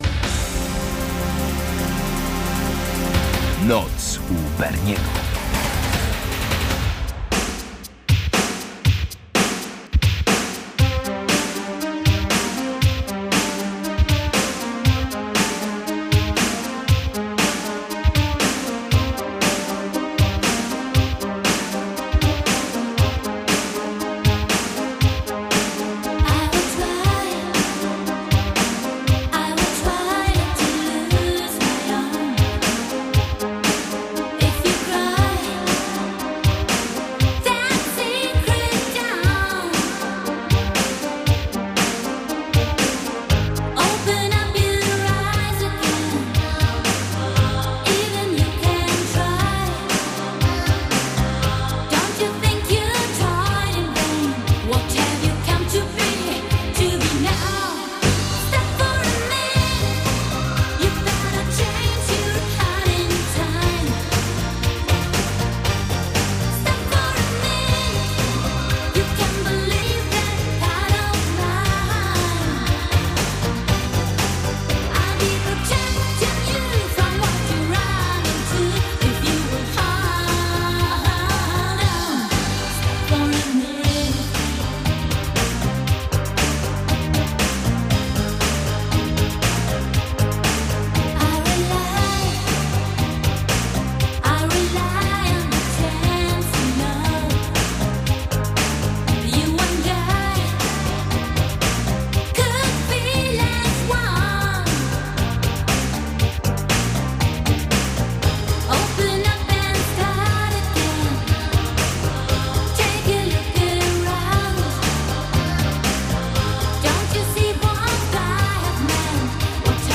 Dominujący gatunek: przeboje większe i mniejsze